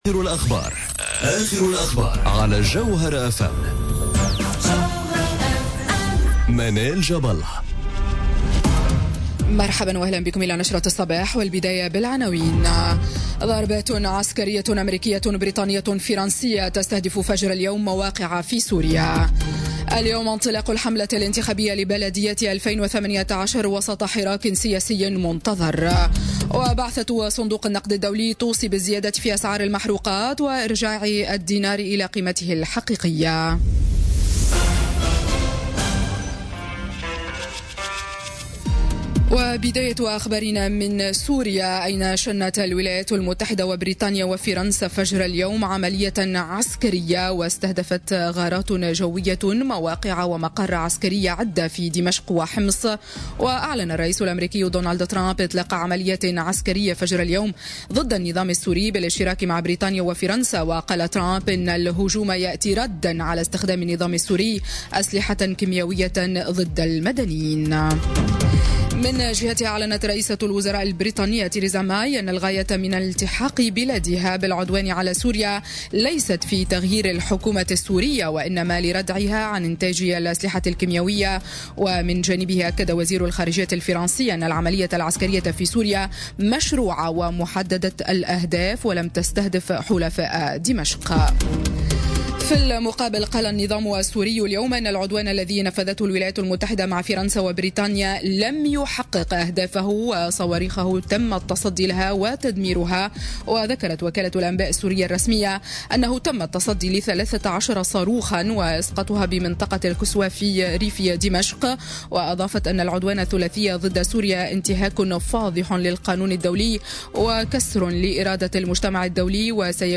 نشرة أخبار السابعة صباحا ليوم السبت 14 أفريل 2018